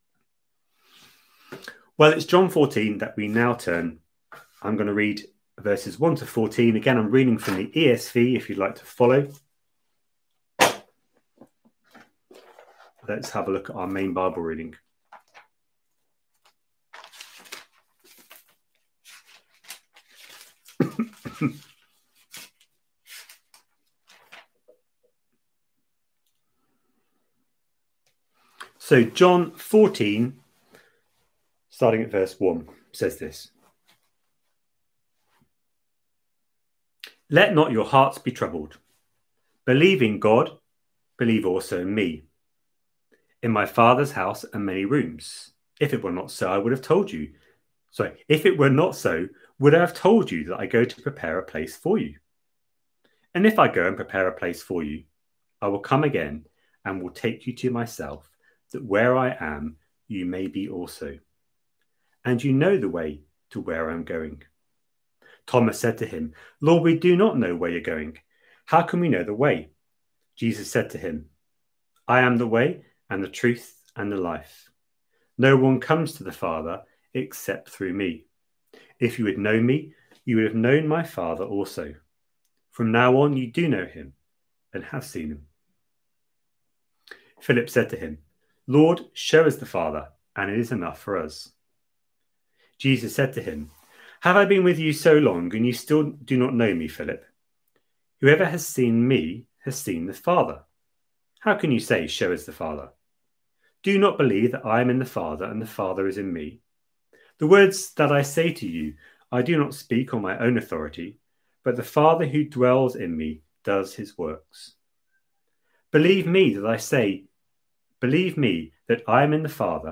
A sermon preached on 2nd August, 2020, as part of our John: The Father's Son series.